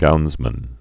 (gounzmən)